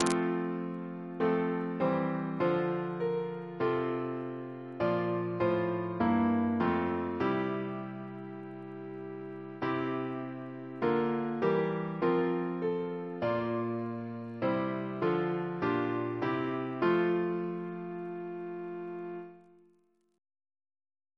Double chant in F Composer: Sir George Elvey (1816-1893), Organist of St. George's Windsor; Stephen's brother Reference psalters: ACB: 311